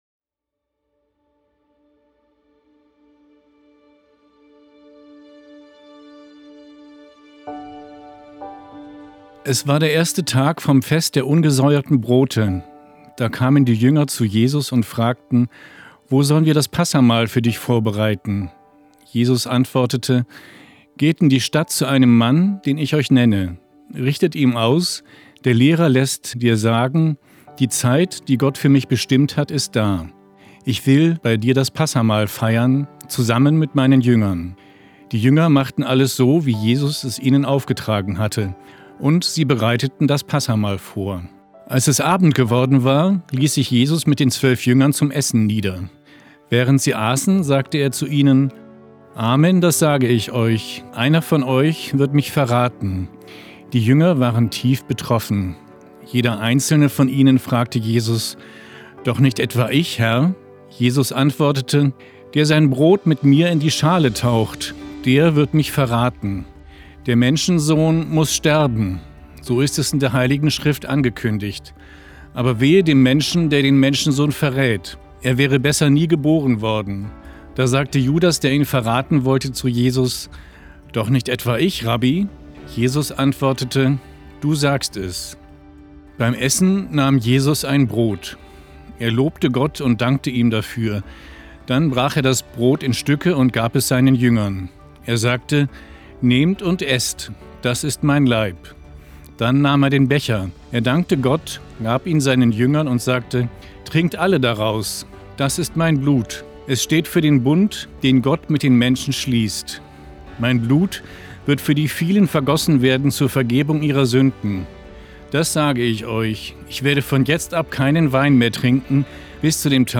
Der Evangelische Kirchenfunk Niedersachsen (ekn) hat die Aufnahmen mit ihm produziert und musikalisch unterlegt.